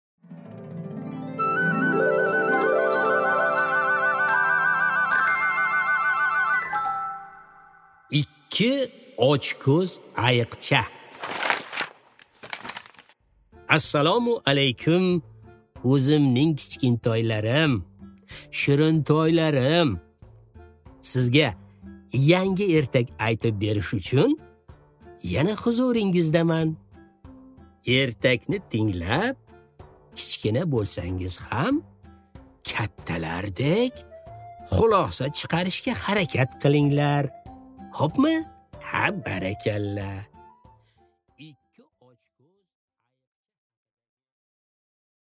Аудиокнига Ikki ochko‘z ayiqcha